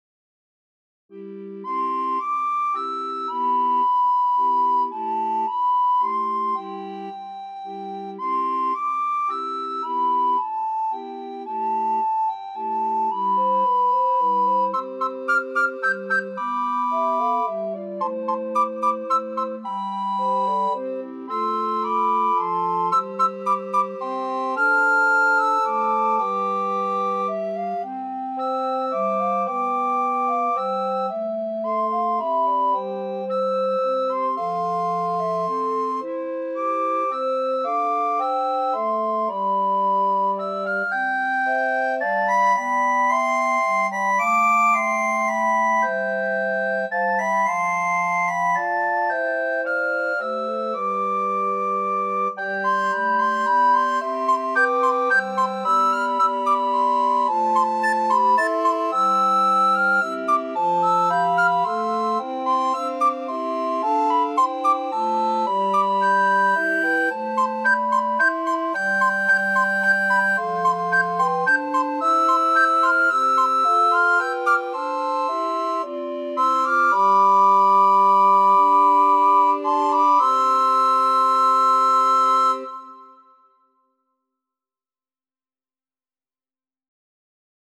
Recorder Ensemble – Beginner/Intermediate
Bursting with joyful energy and optimism